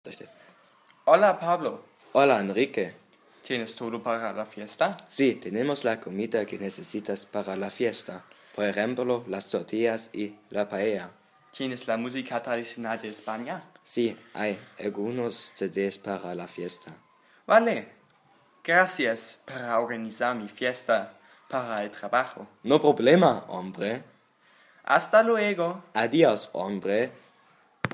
Una conversación en una oficina.